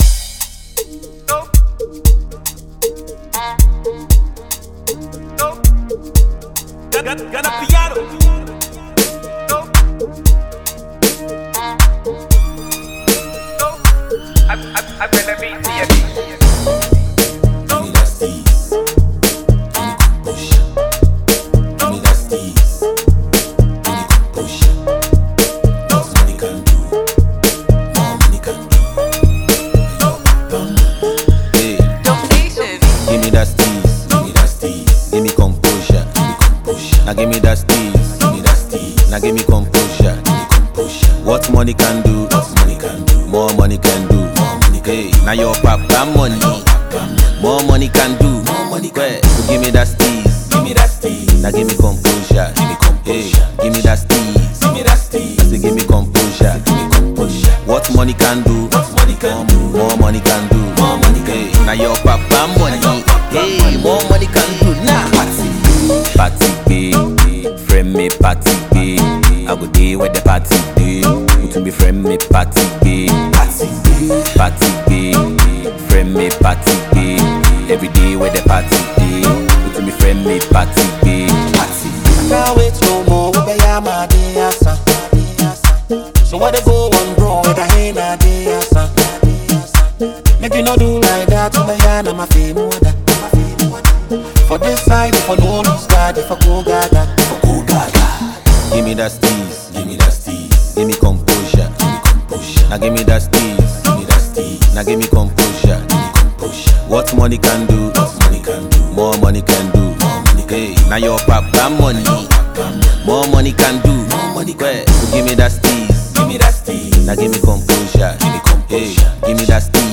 high-energy banger